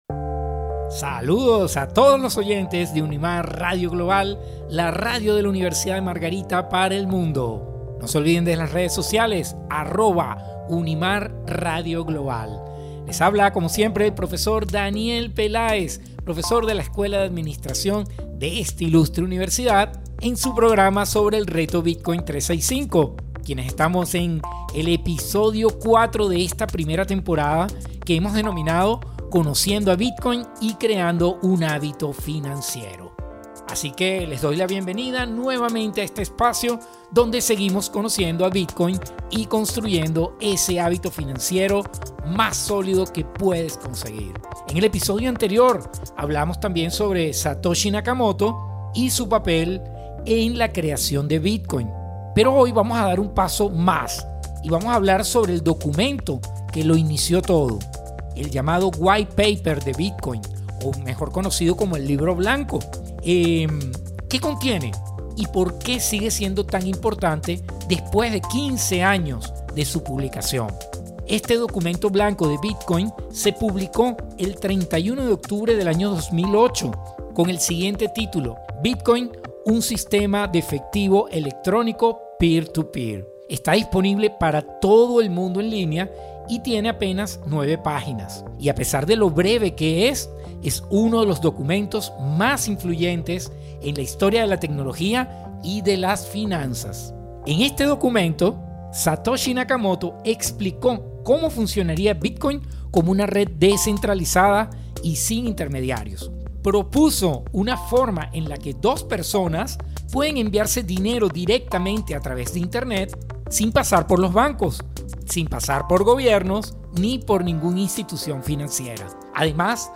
A través de entrevistas, testimonios y cápsulas informativas, los oyentes aprenderán estrategias prácticas para invertir de manera sostenida en Bitcoin utilizando el método de Dollar Cost Averaging (DCA).